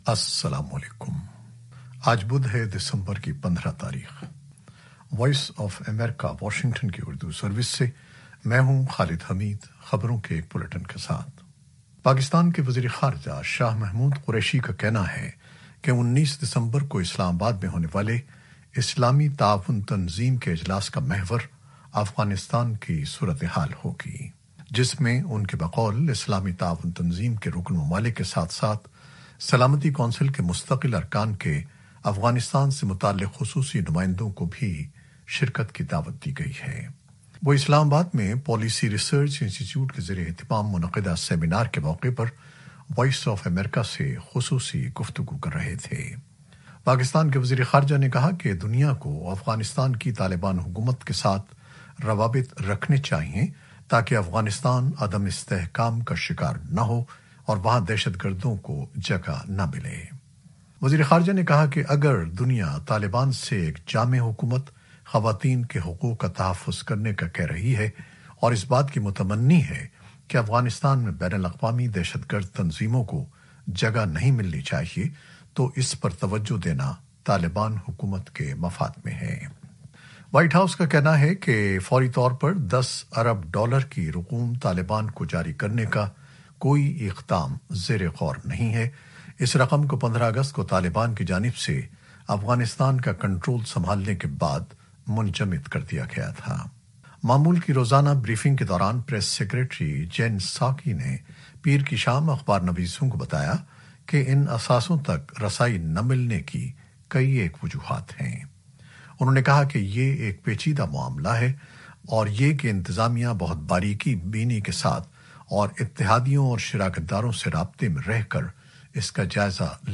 نیوز بلیٹن 2021-15-12